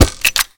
sci-fi_weapon_reload_08.wav